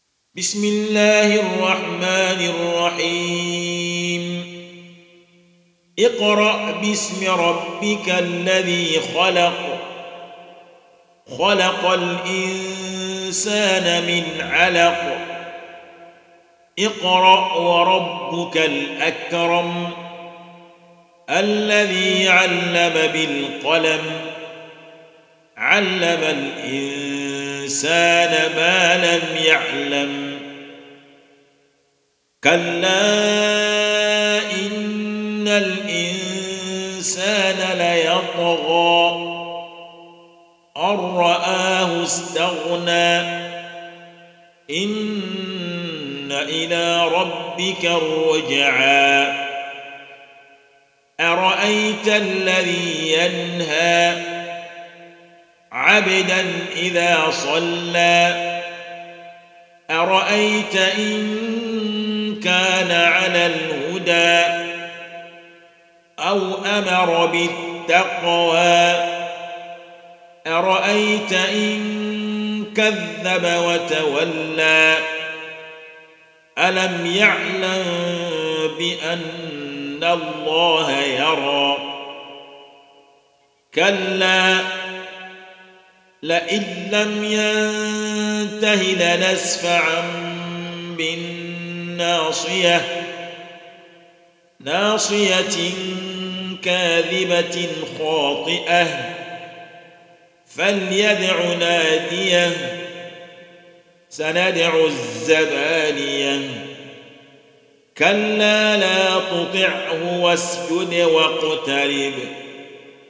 Lecture